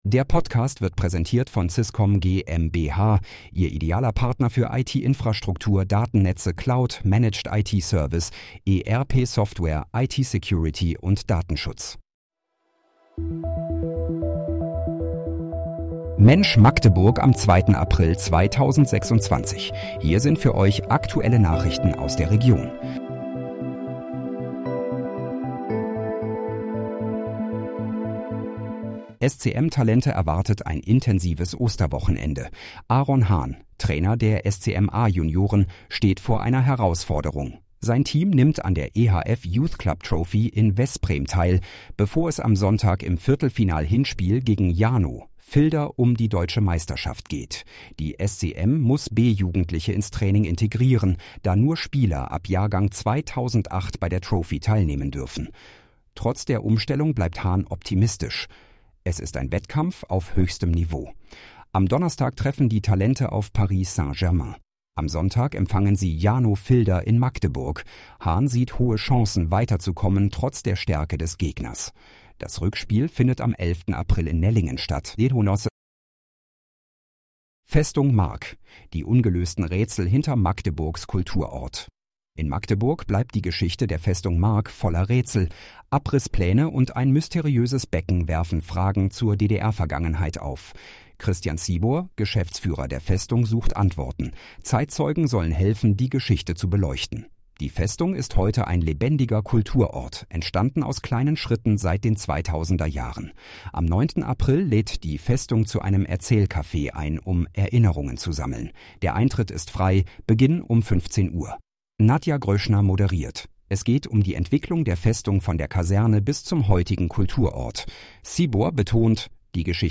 Mensch, Magdeburg: Aktuelle Nachrichten vom 02.04.2026, erstellt mit KI-Unterstützung